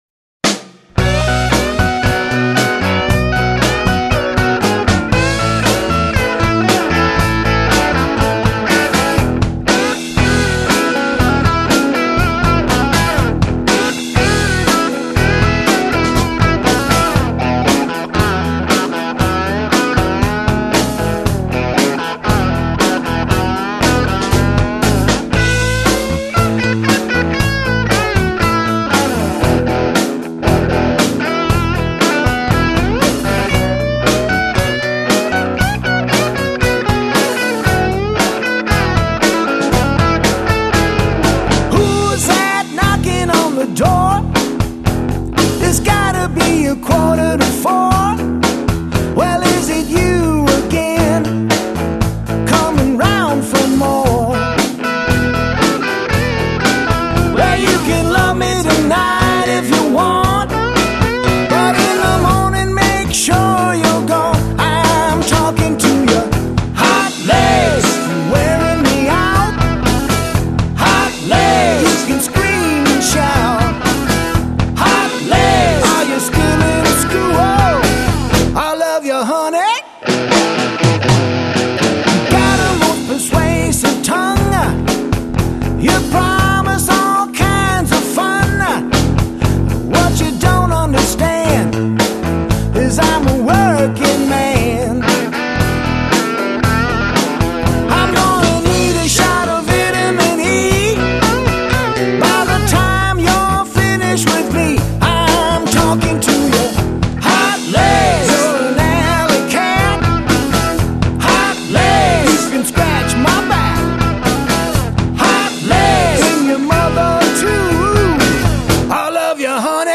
studio album, I sing & play